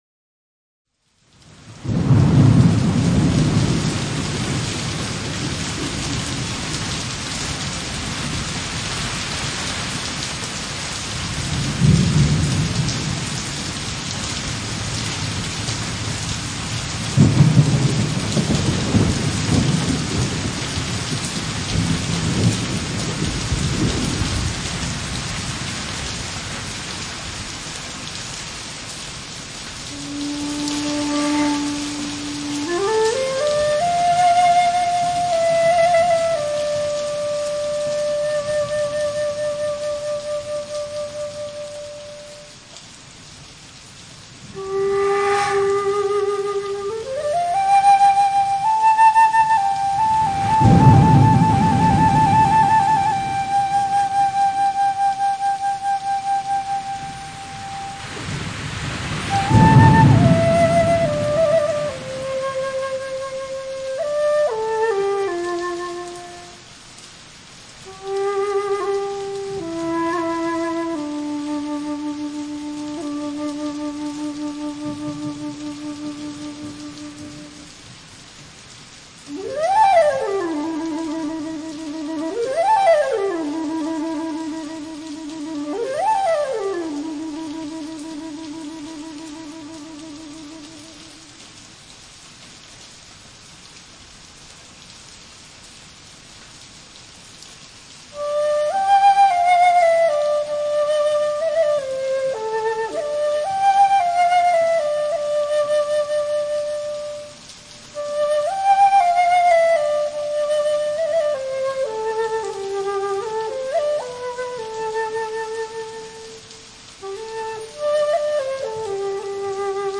凉夜如水，细雨沙沙。
雷声隆隆，雨越下越大。
滴滴瀝瀝，绵绵不尽。